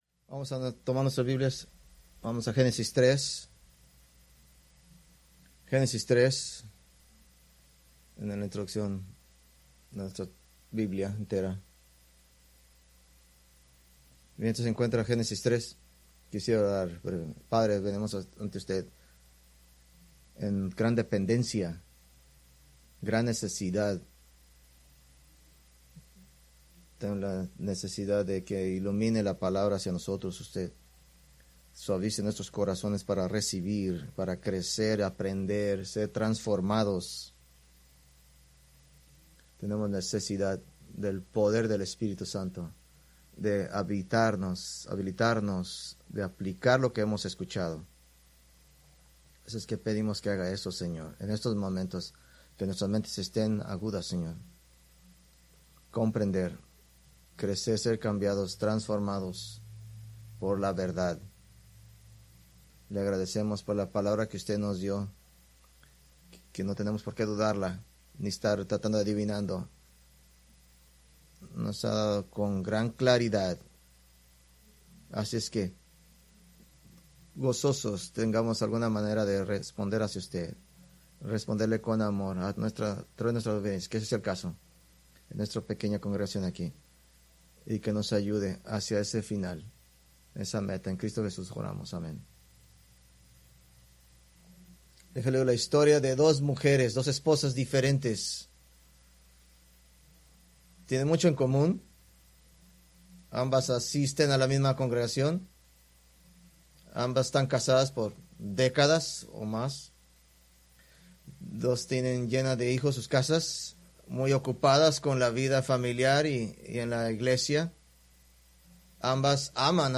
Preached August 10, 2025 from Escrituras seleccionadas